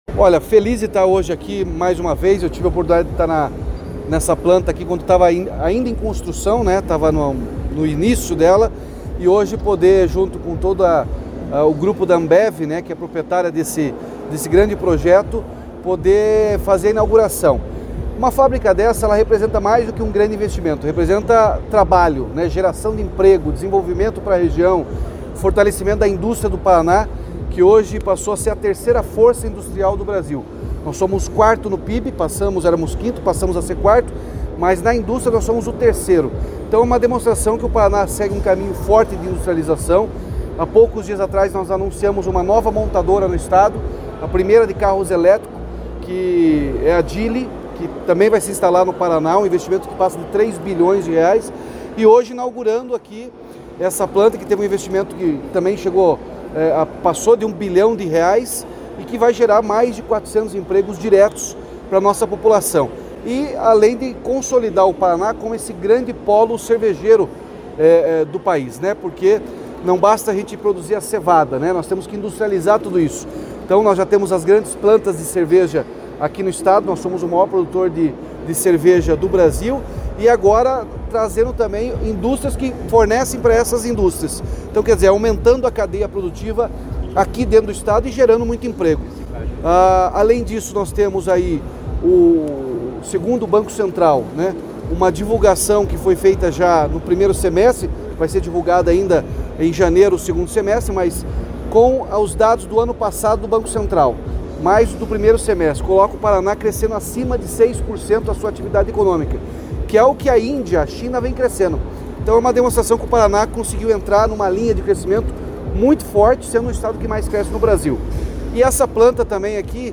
Sonora do governador Ratinho Junior sobre a inauguração da nova fábrica de garrafas de vidro da Ambev em Carambeí